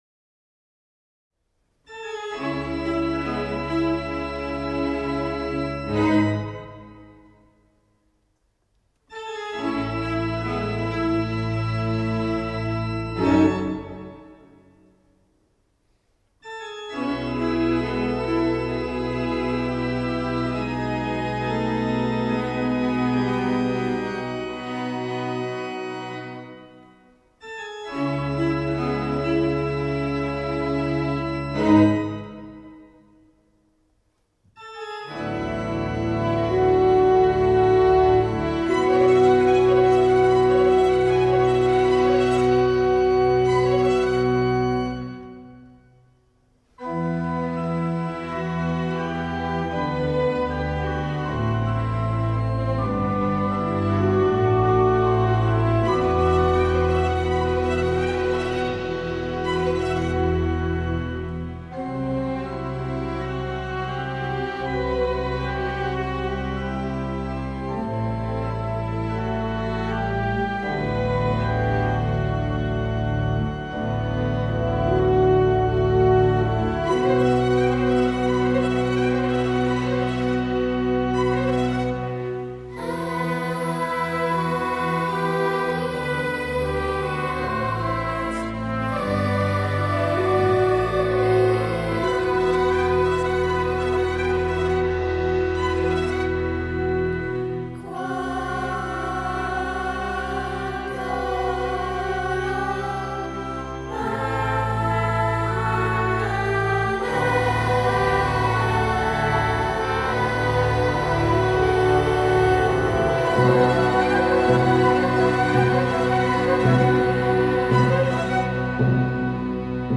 Μέγαρο Μουσικής Αθηνών